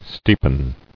[steep·en]